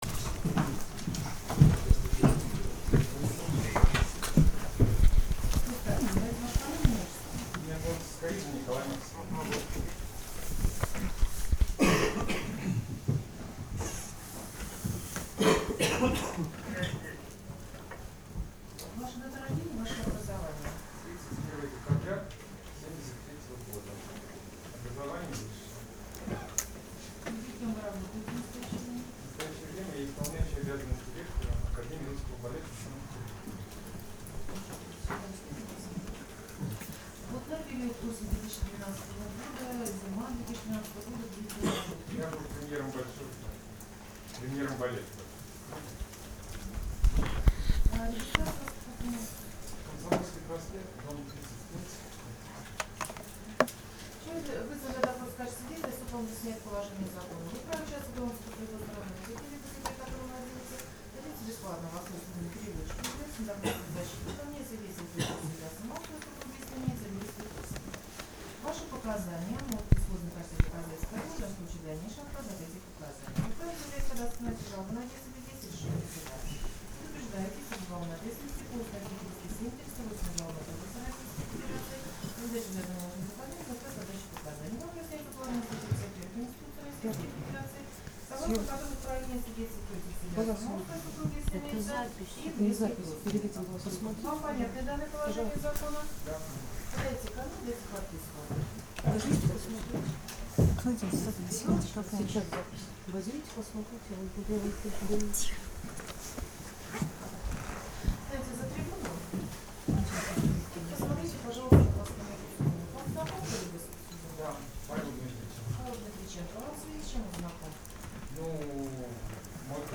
Николай Цискаридзе в суде - о Дмитриченко и Филине